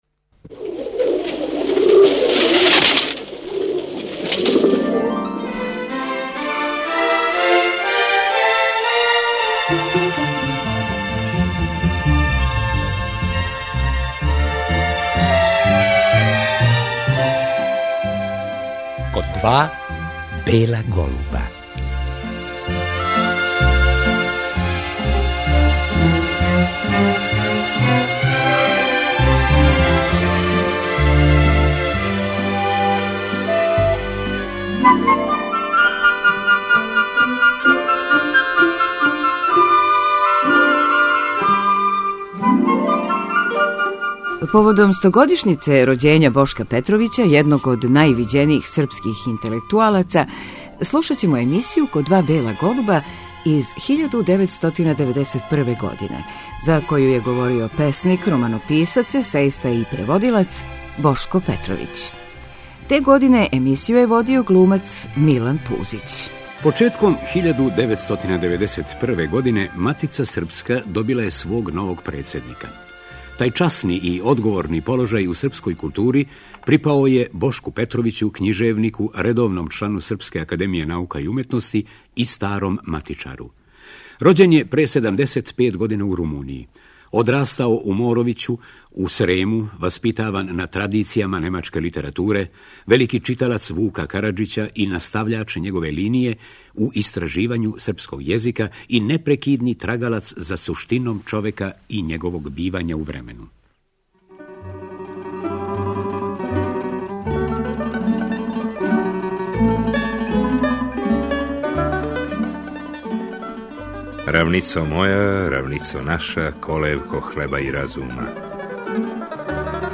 Разговор са академиком вођен је некако после његовог избора за председника Матице српске.